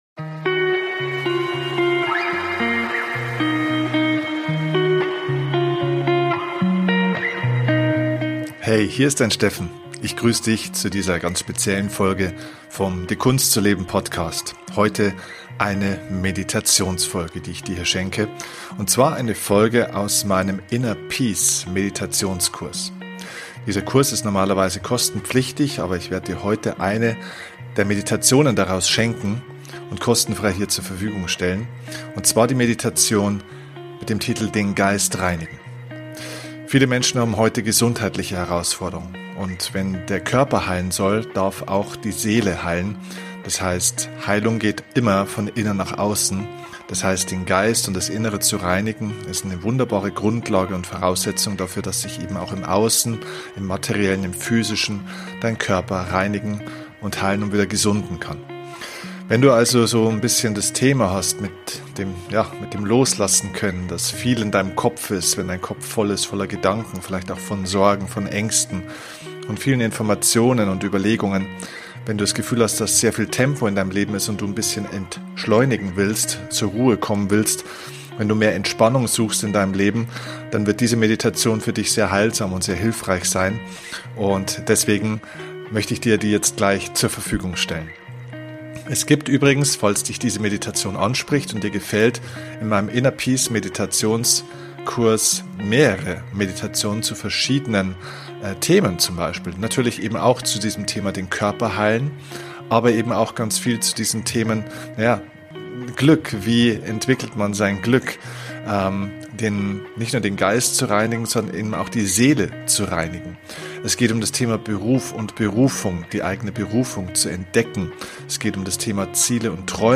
Heute bekommst Du von mir eine kostenlose Meditation aus meinem Meditationskurs. In der heutigen Meditation geht es darum, den Geist zu reinigen.